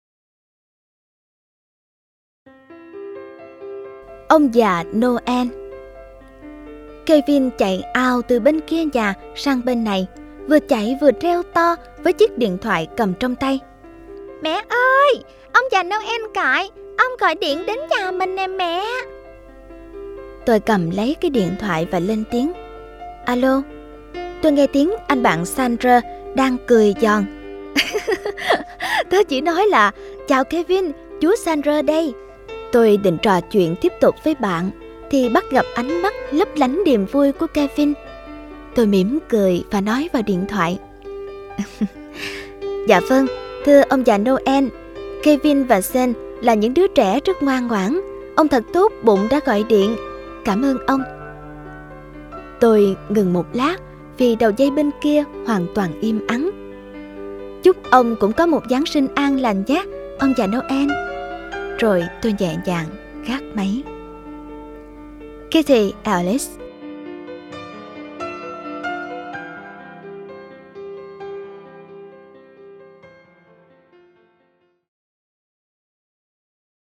Sách nói Chicken Soup 20 - Hạt Giống Yêu Thương - Jack Canfield - Sách Nói Online Hay